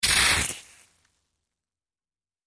Звуки утюга
Звук капли воды на раскаленную подошву утюга